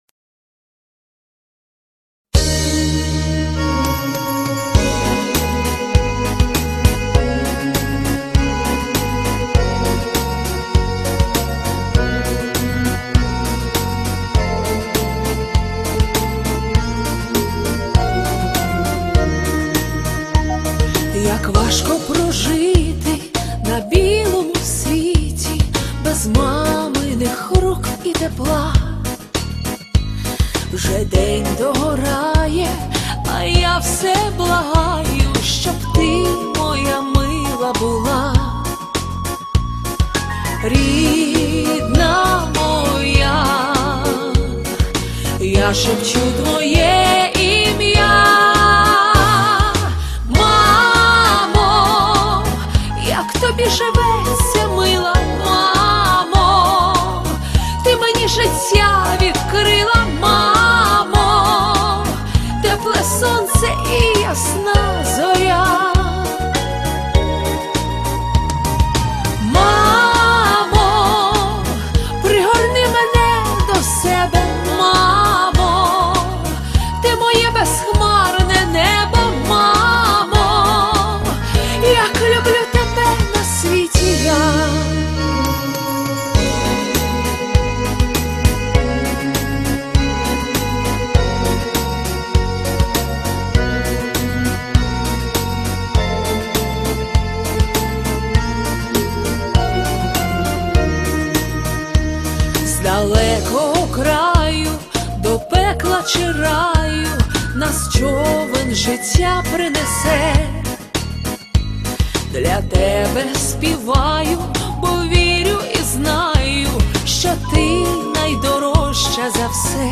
Пісня про МАМУ.mp3